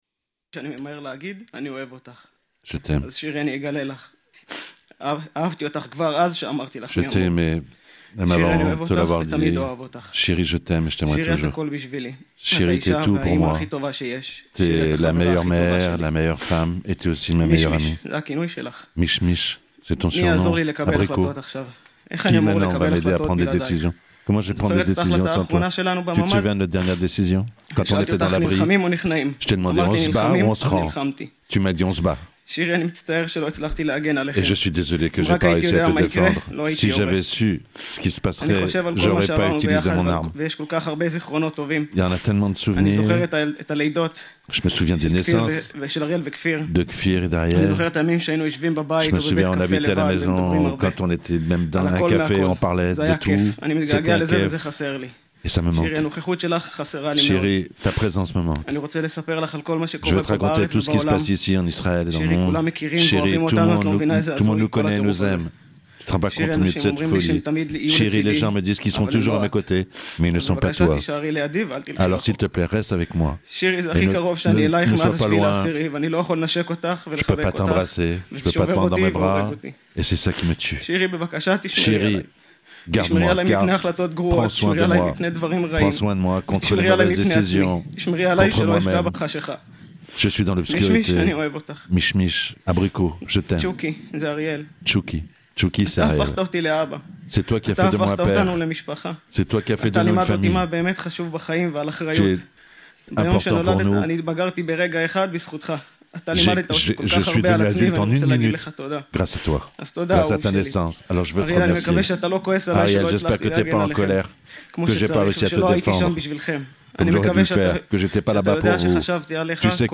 Les quelques mots de Yarden Bibas à l'enterrement de sa famille traduits en français